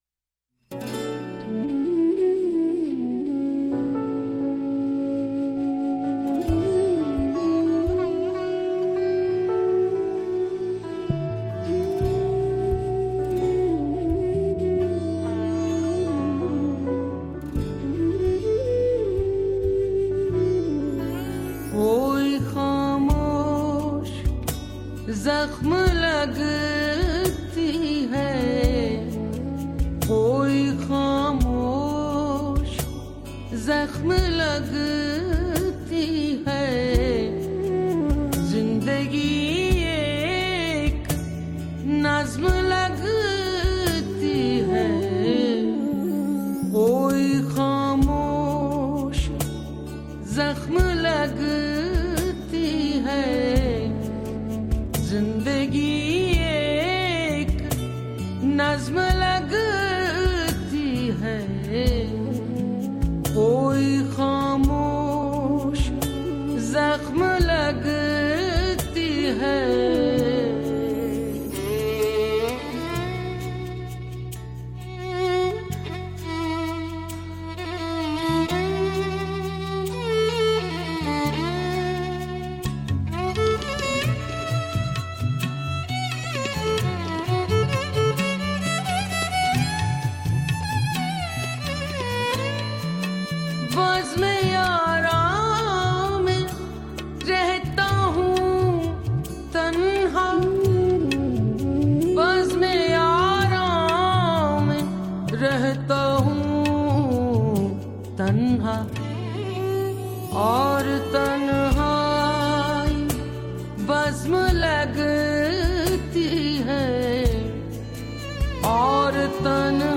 Urdu Ghazal